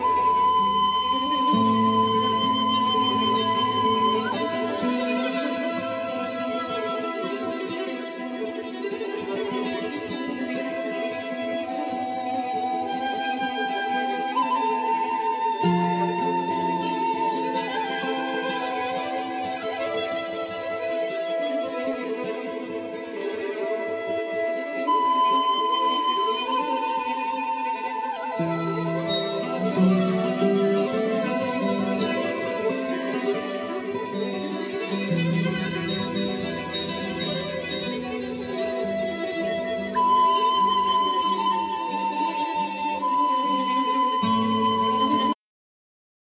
Flute,Piano,Percussion
Violin
Soprano Saxophne,Tenor Saxphone
Acoustic guitar,Dombra,Bayan